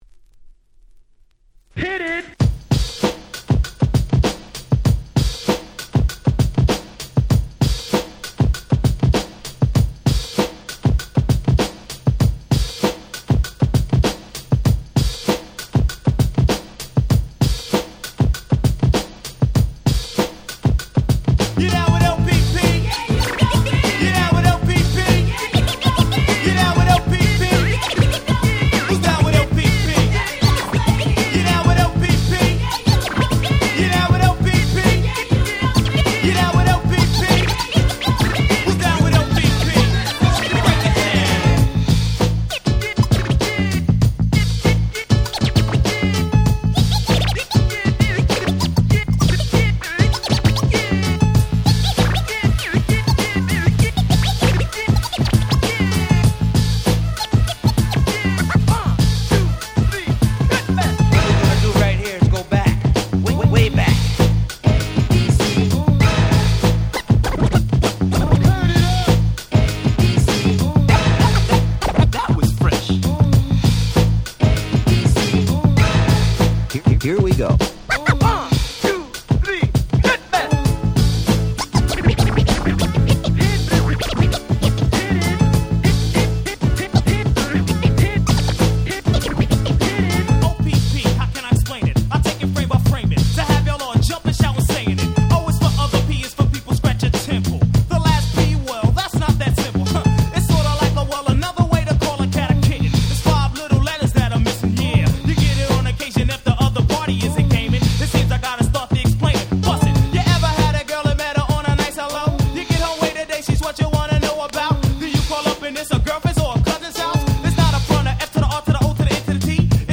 91' Super Hip Hop Classics !!